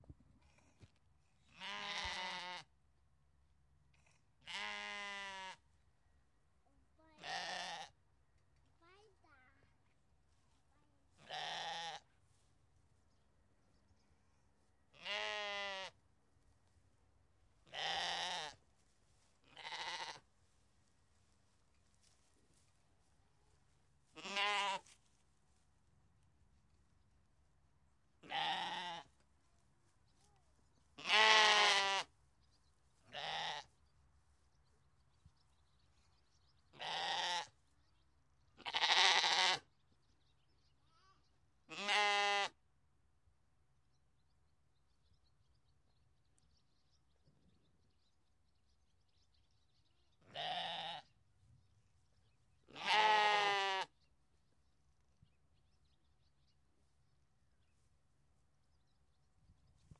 农场 " 比利时绵羊
描述：在比利时东南部Famenne地区的Nassogne村附近，几只羊在栅栏的另一侧，距离约2米，在我面前咩咩叫。
Tag: 农业 鸣叫 农村 农场